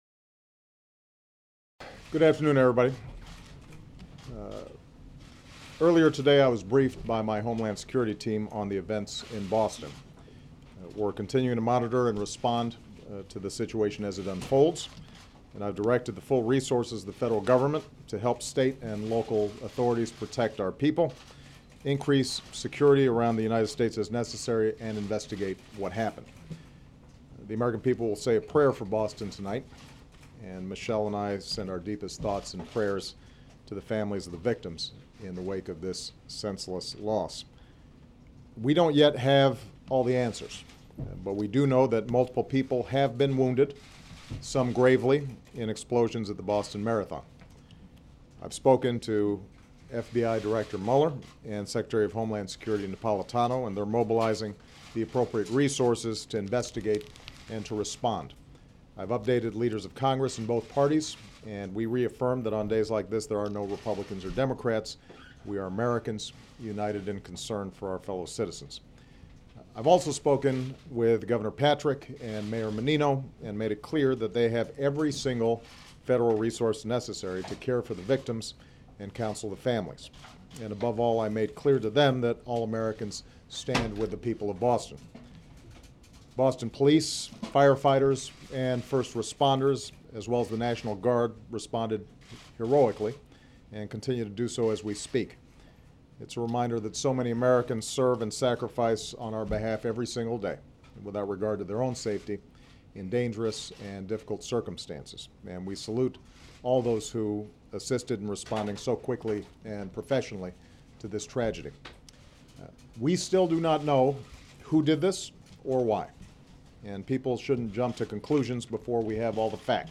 U.S. President Barack Obama speaks to reporters about bomb explosions that detonated near the finish line of the Boston Marathon earlier in the day